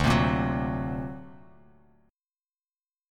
D+7 Chord
Listen to D+7 strummed